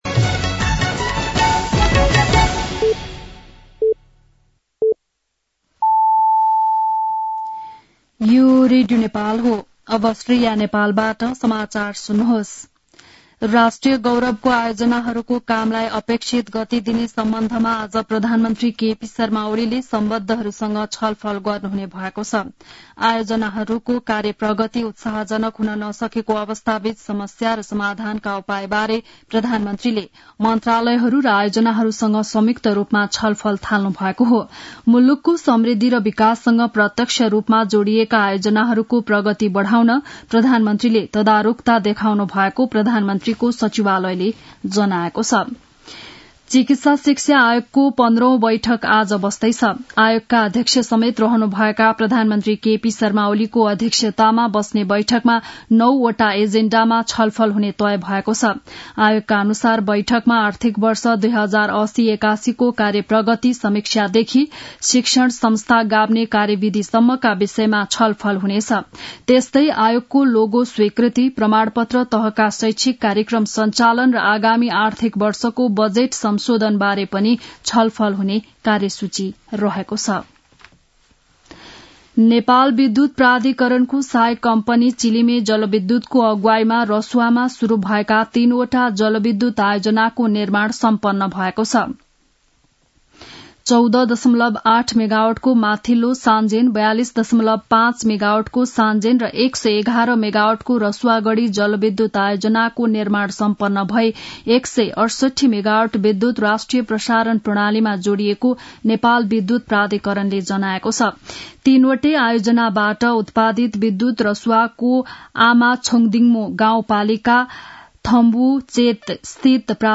बिहान ११ बजेको नेपाली समाचार : १० मंसिर , २०८१